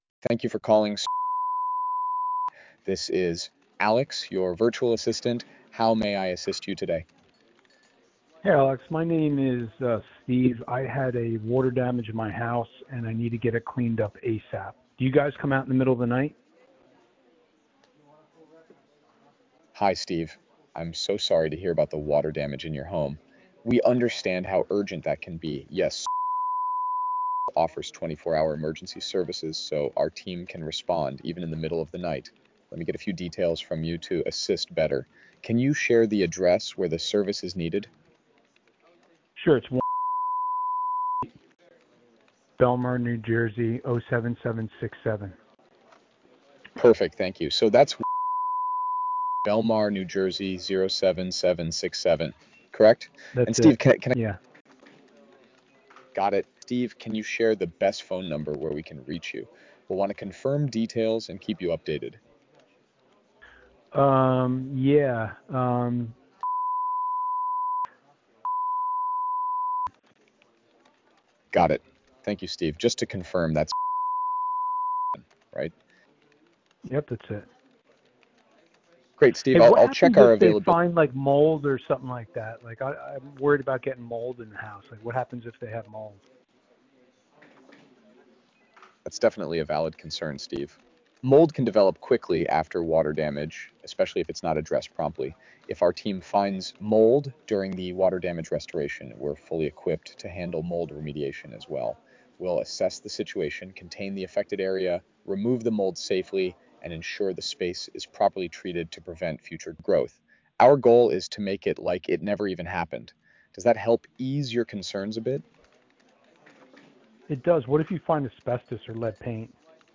Spanish and English AI agents ready to help your business grow
Sample Water Remediation Call